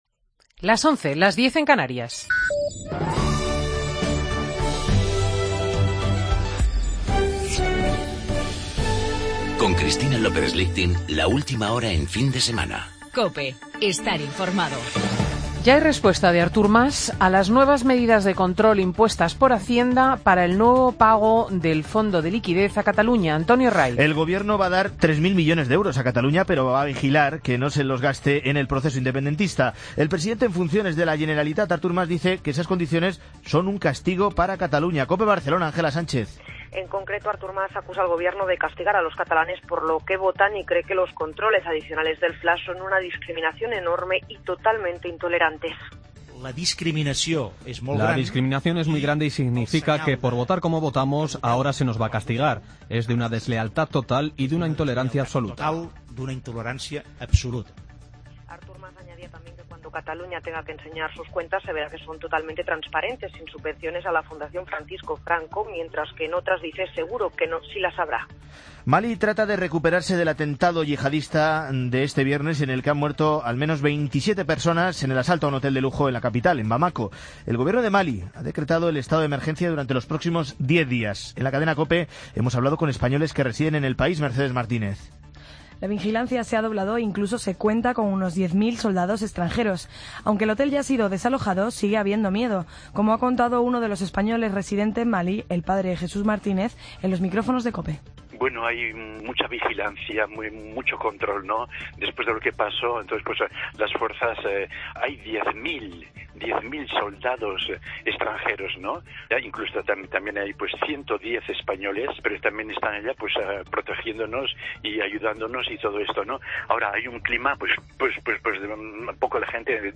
Noticias de las 11h del sábado 21 de noviembre de 2015